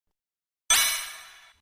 Sans Eye Sounds
sans-eye-sounds.mp3